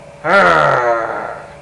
Grunt Sound Effect
Download a high-quality grunt sound effect.
grunt-2.mp3